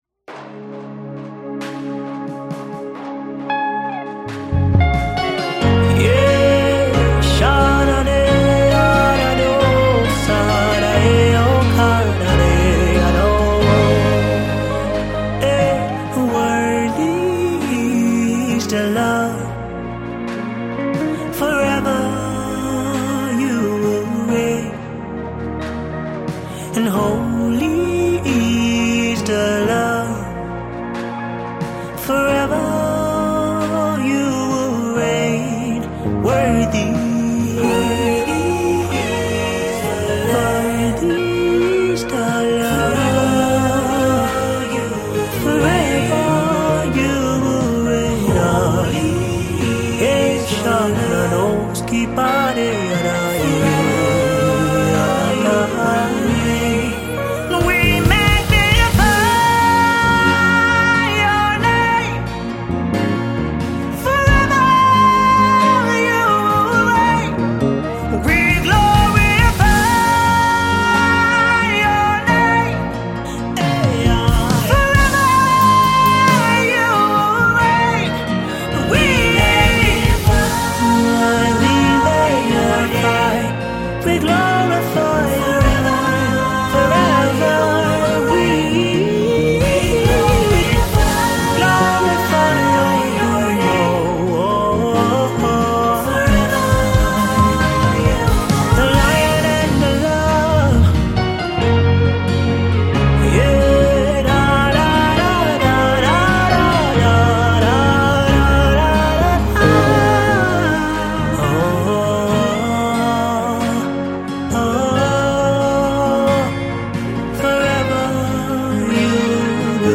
Fast rising gospel singer-songwriter